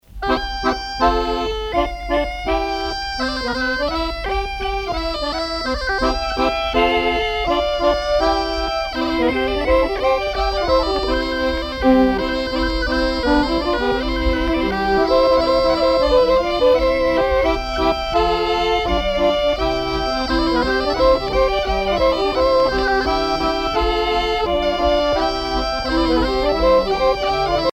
Chants brefs - A danser
danse : scottich sept pas
Pièce musicale éditée